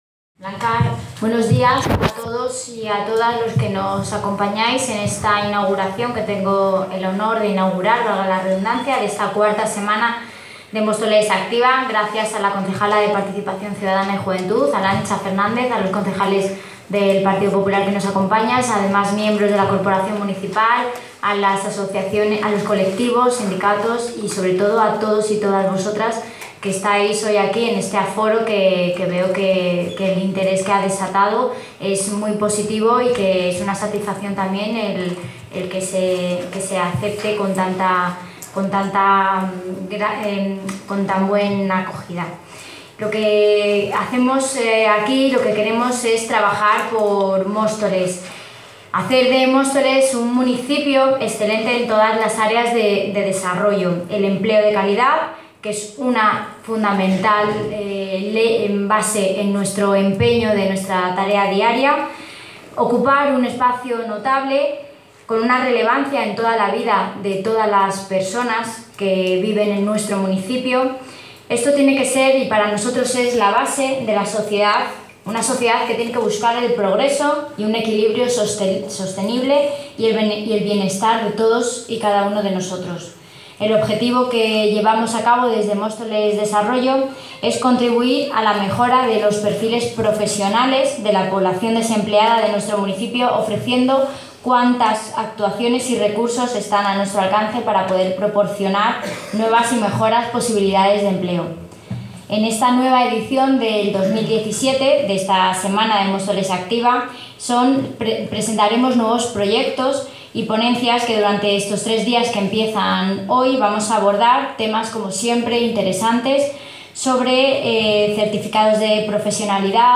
Audio - Jéssica Antolín (Concejala de Desarrollo Económico, Empleo y Nuevas Tecnologías)